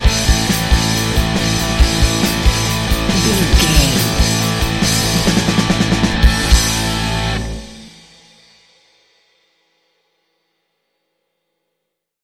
Ionian/Major
electric guitar
drums
bass guitar
hard rock
aggressive
energetic
intense
nu metal
alternative metal